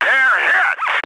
hit1.ogg